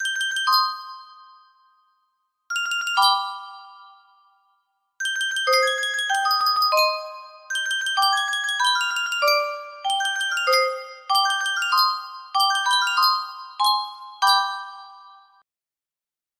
Yunsheng Music Box - Beethoven Symphony No. 5 1st Movement 4008 music box melody
Full range 60